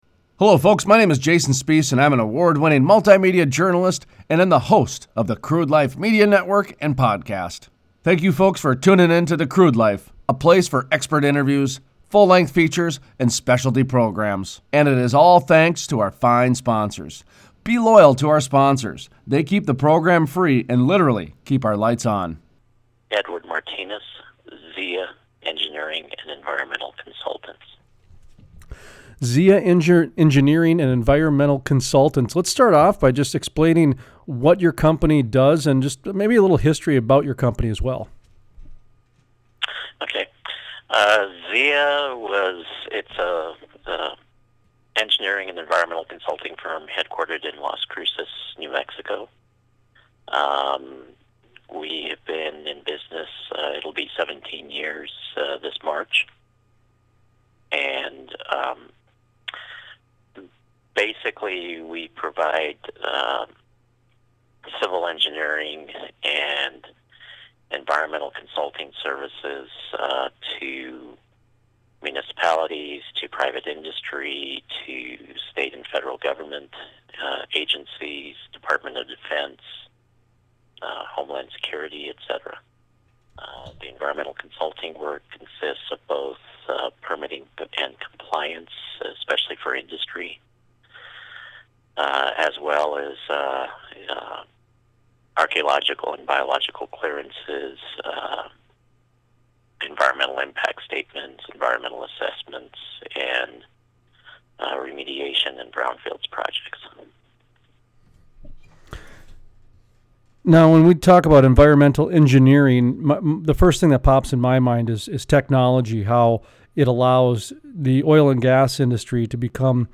Full length interview: Why clean refineries of the future are here today - The Crude Life